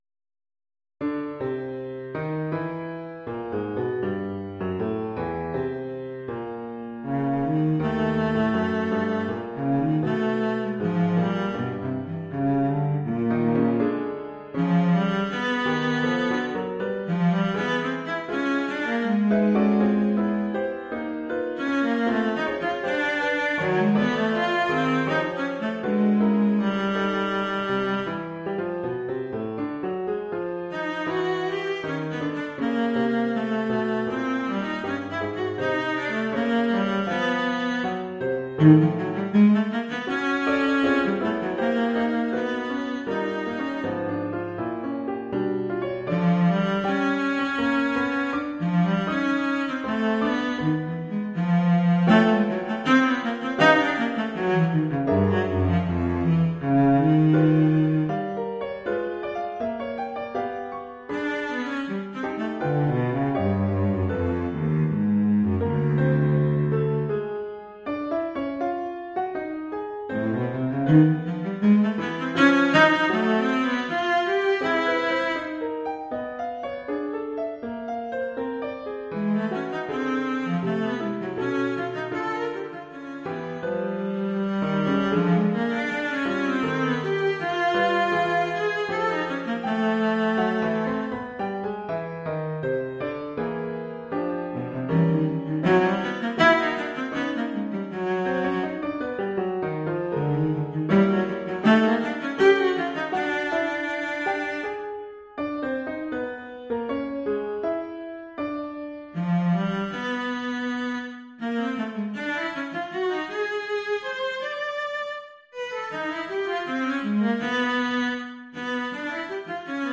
Oeuvre pour violoncelle et piano.
La pièce comporte quatre parties distinctes.